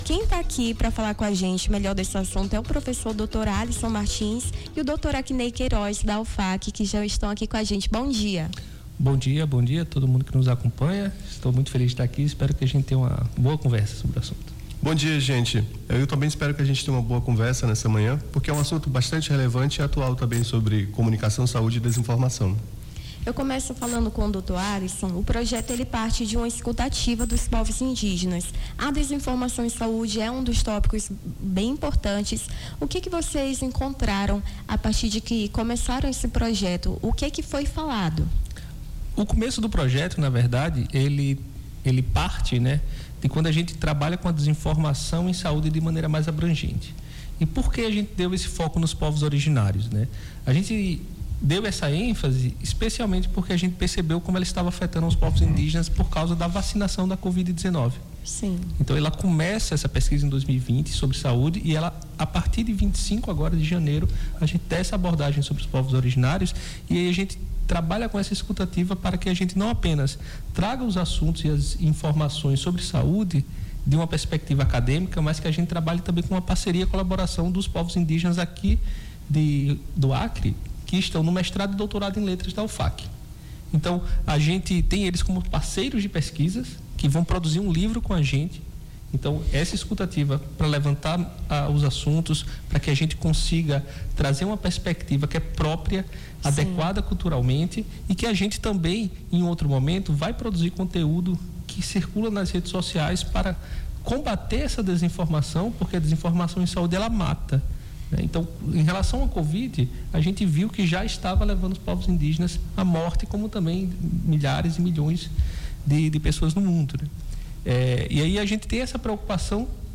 Nome do Artista - CENSURA - ENTREVISTA (SAUDE INDIGENA) 16-07-25.mp3